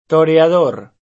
toreador [sp.